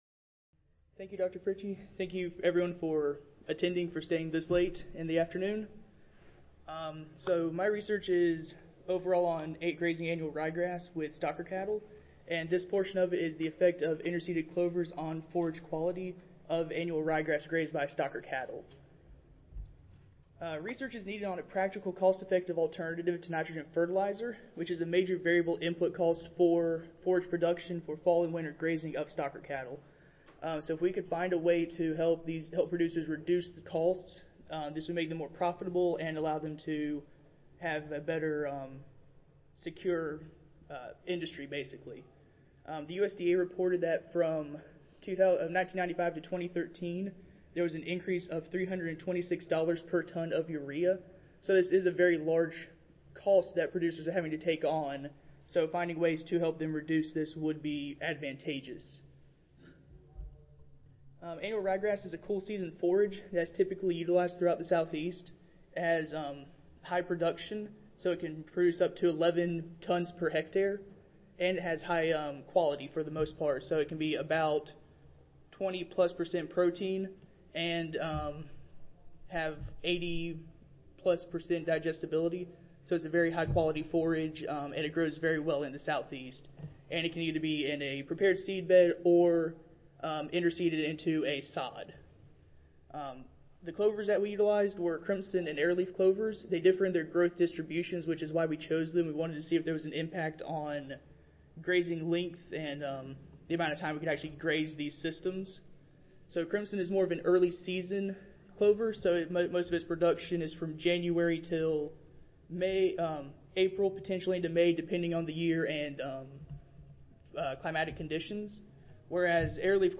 See more from this Division: C02 Crop Physiology and Metabolism See more from this Session: Graduate Student Oral Competition